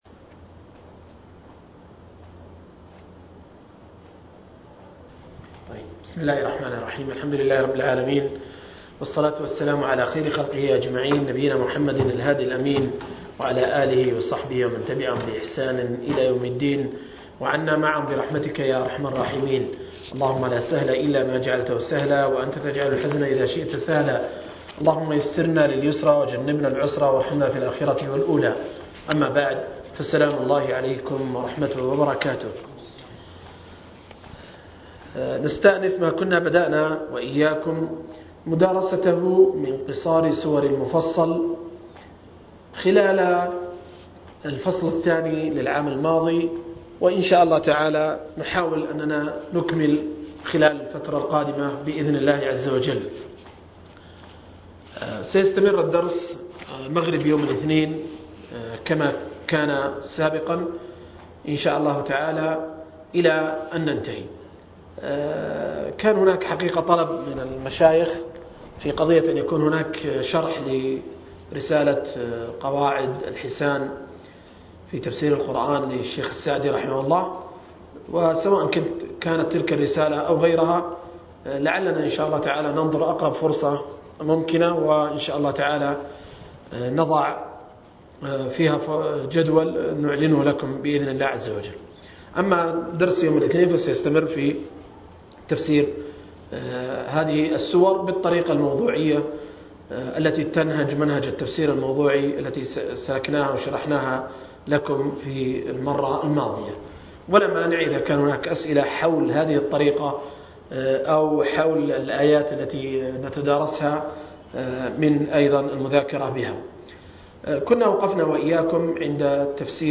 012-التفسير الموضوعي الميسر لقصار المفصل – الدرس الثاني عشر